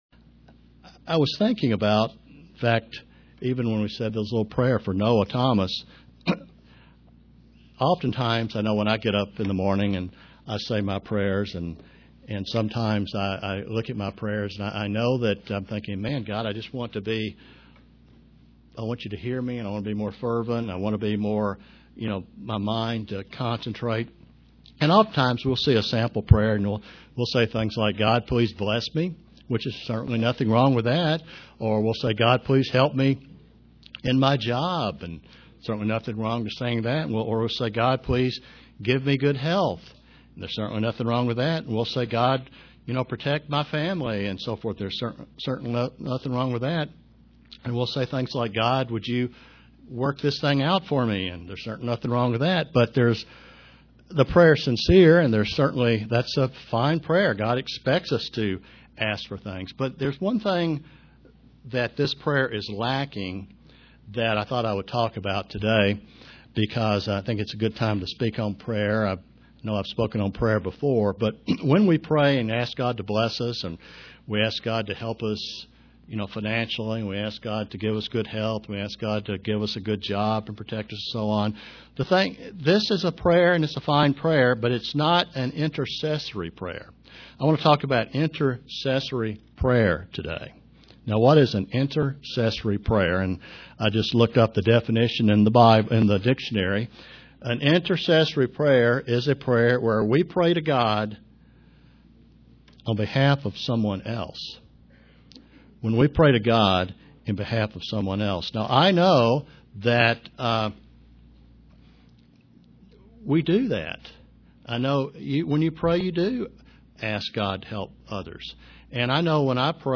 Print God expects us to pray for one another UCG Sermon Studying the bible?